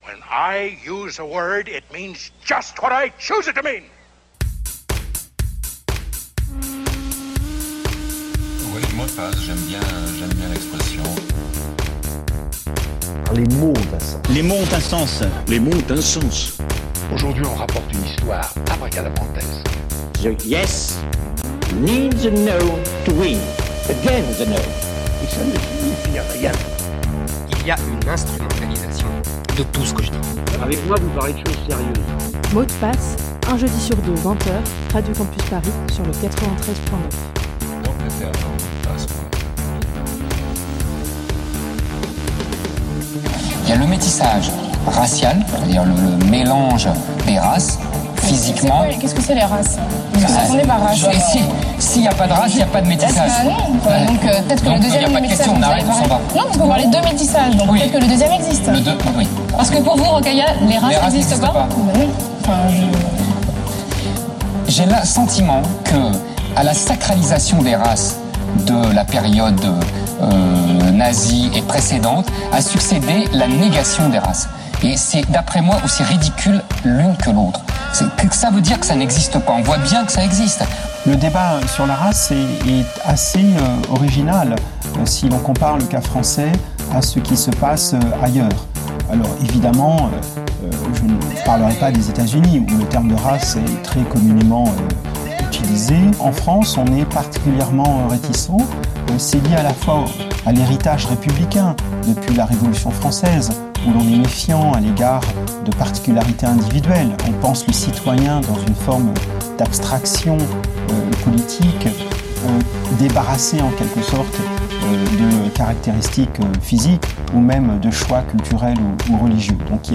Invitée
Mots de passe reçoit Houria Bouteldja, militante "décoloniale" attachée à l' "autonomie indigène" au sein des luttes, est co-fondatrice du Parti des Indigènes de la Républiques, et membre de Paroles d'Honneur, qui publie Beaufs et Barbares: le pari du nous, aux éditions la Fabrique.